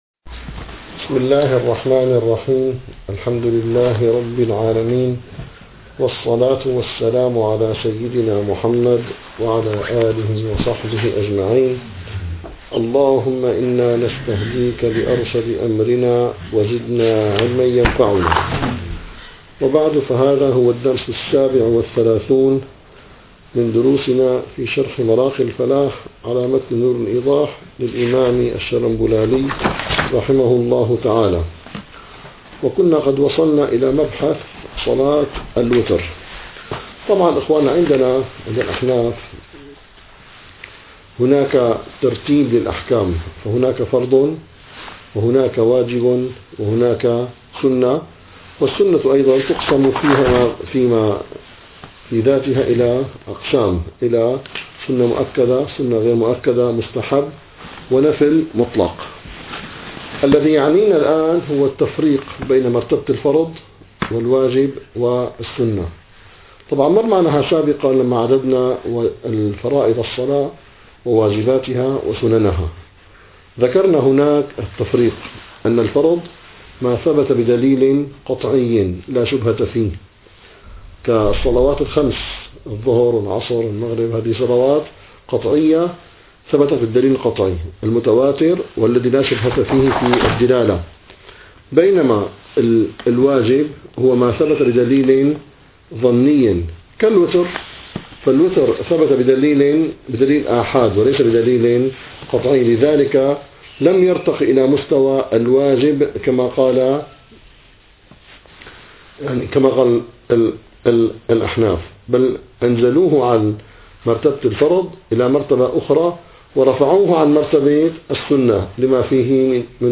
- الدروس العلمية - الفقه الحنفي - مراقي الفلاح - 37- صلاة الوتر